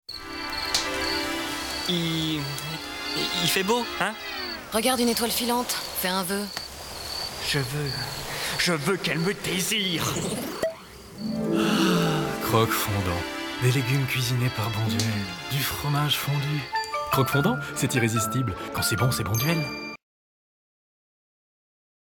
Voix off
40 - 59 ans - Baryton-basse